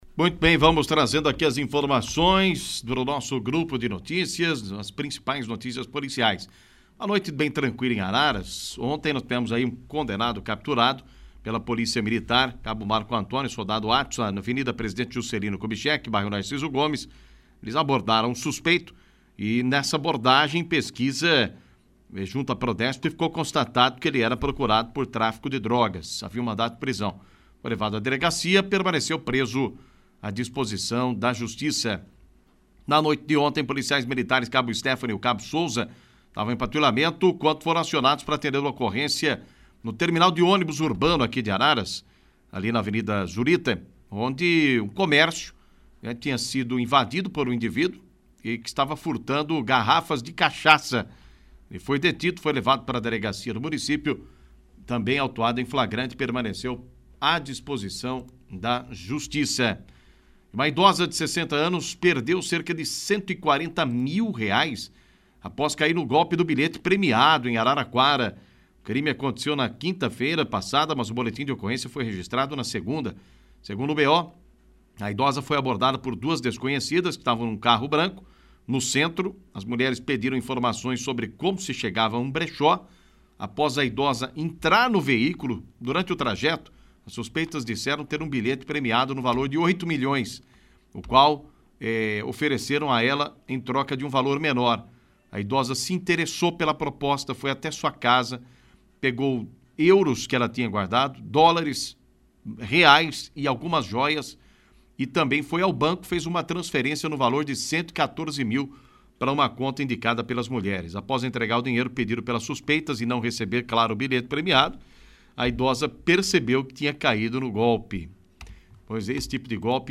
Polícia